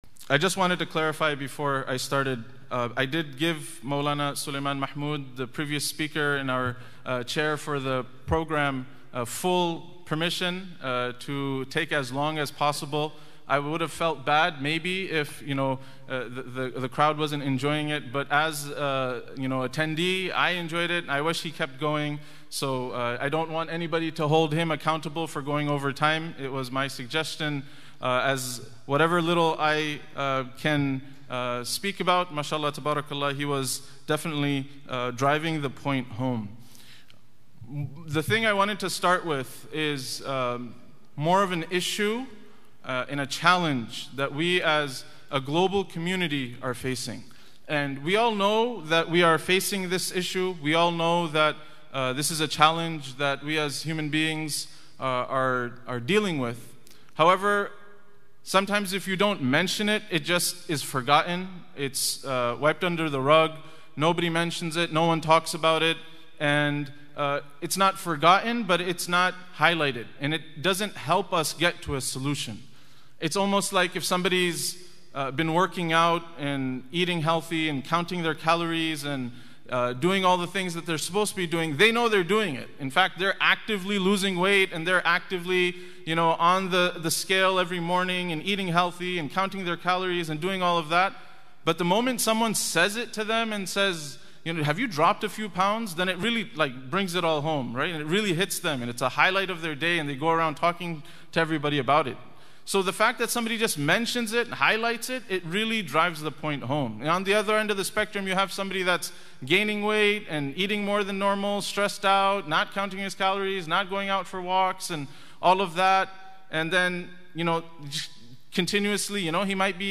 Sirah Conference 2025